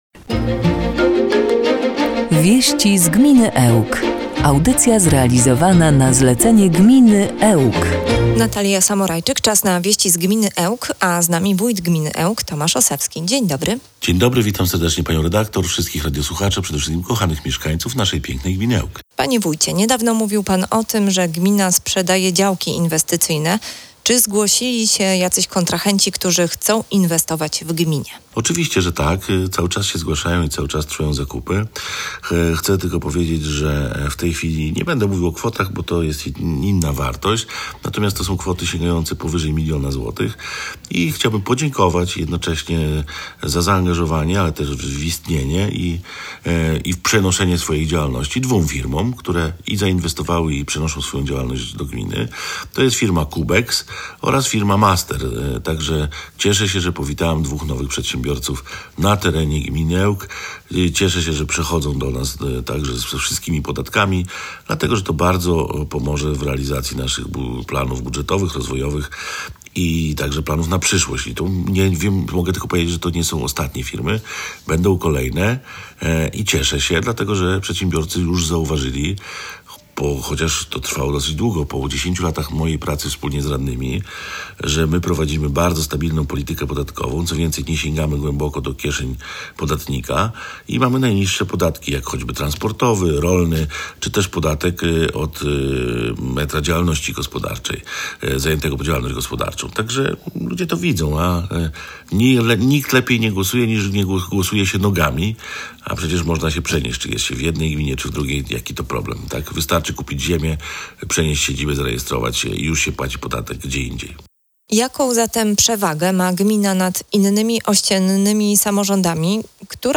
O nowych przedsiębiorcach, którzy zdecydowali się zainwestować na terenie gminy, mówił w audycji „Wieści z gminy Ełk” włodarz, Tomasz Osewski. Wójt zachęcał również mieszkańców do udziału w konkursie na najpiękniejszy wieniec dożynkowy.
01-08_WIESCI-Z-GMINY-ELK-Z-JINGLAMI.mp3